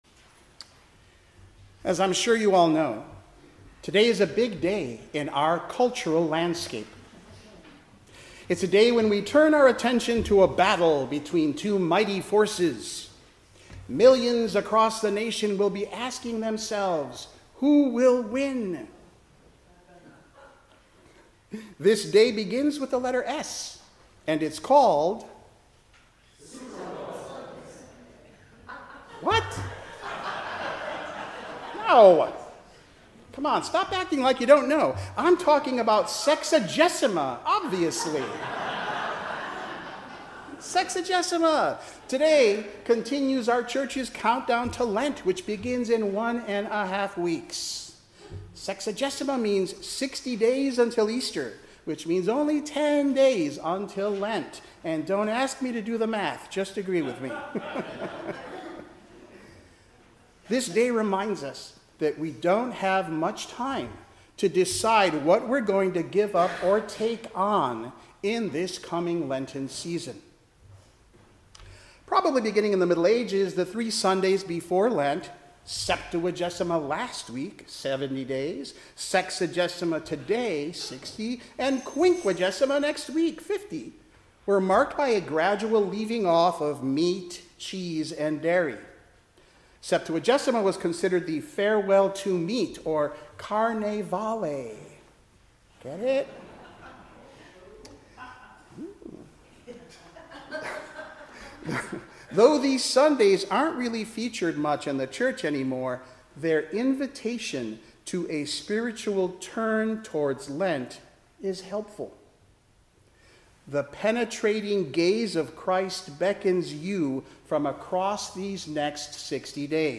Service Type: 10:00 am Service
Sermon_-Fifth-Sunday-after-Epiphany-February-8-2026.mp3